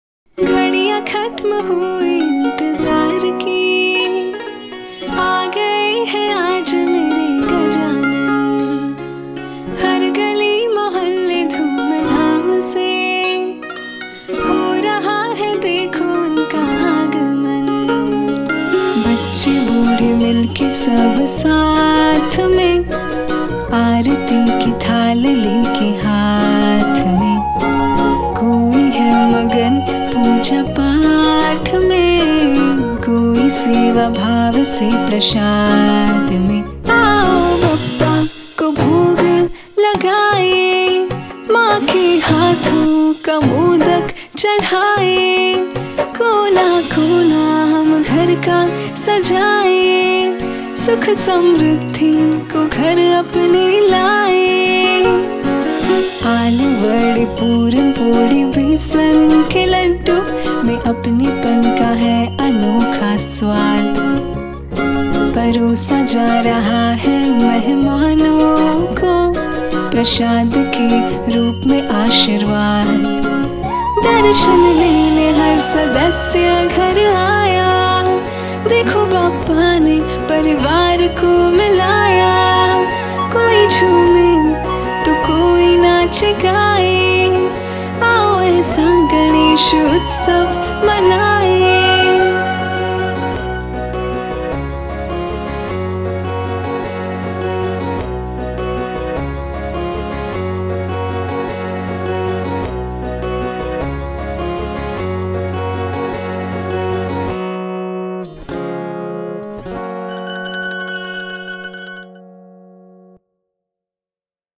Client Song Example
• Ad Jingles